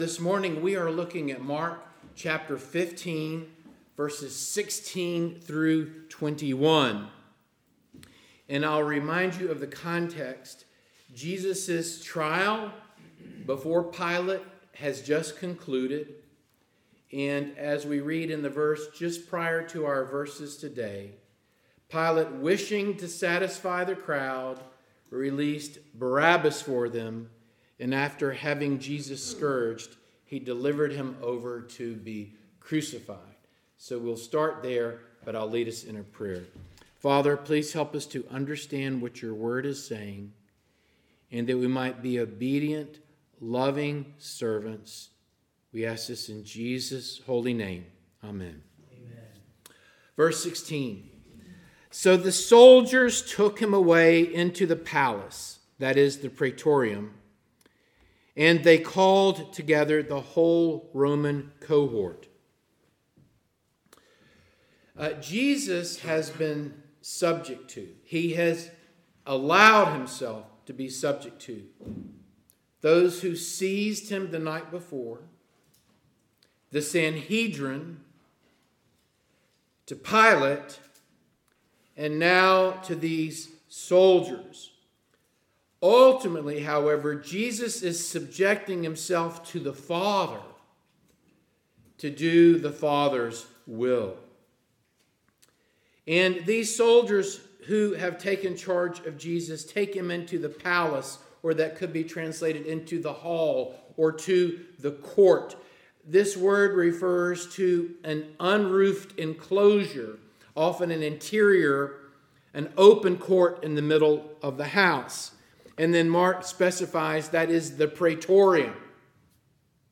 Mark 15:16-21 Service Type: Morning Service Download Files Bulletin « “They Cried Out